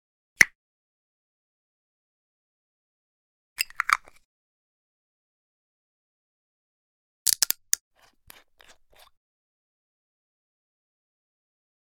household
Bottle Glass Small Juice Open Twist Top 2